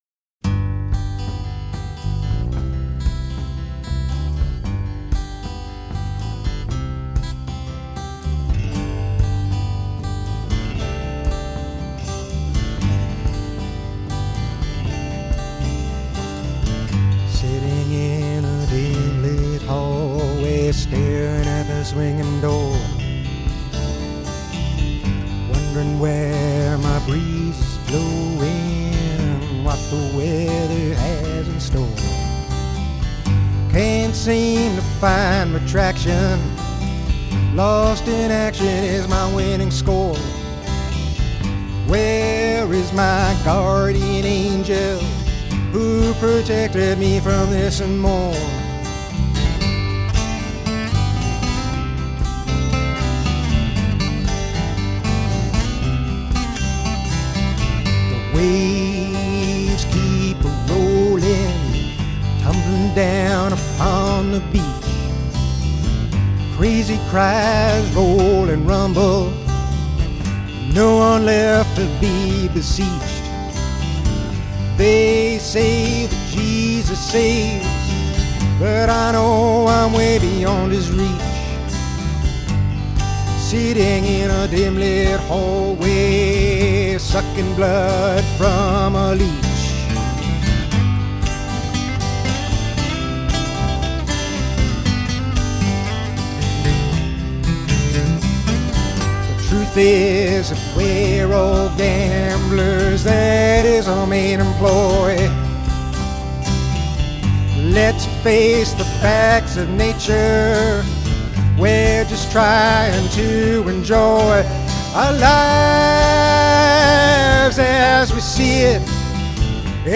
Vintage Live & Rehearsal Recordings